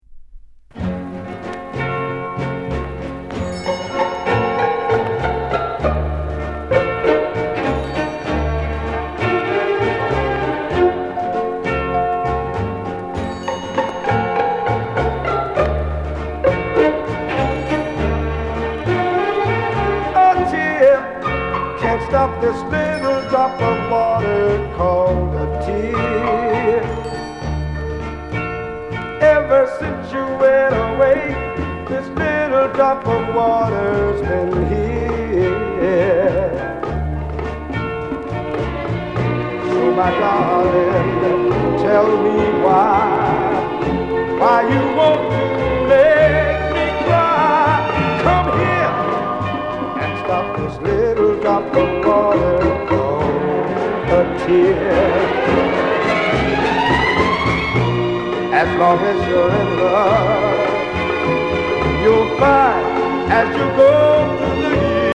Sweet Soul